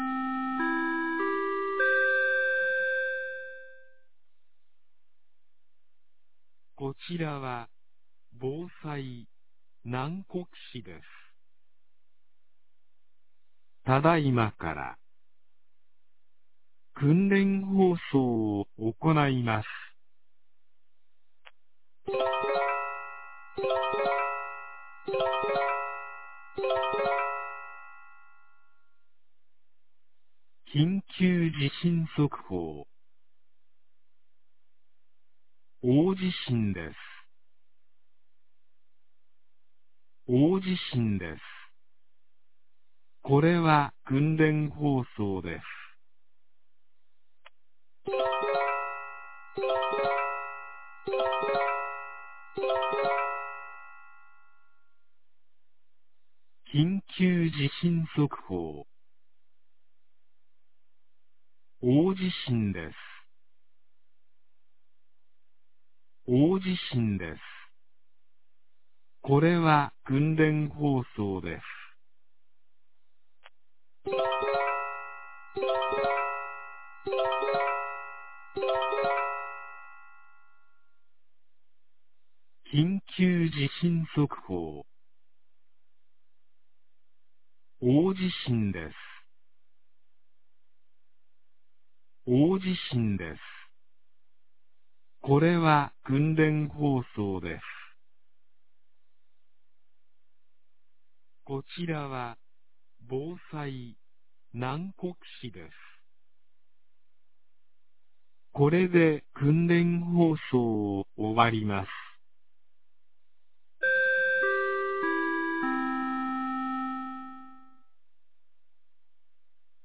2022年06月15日 10時01分に、南国市より放送がありました。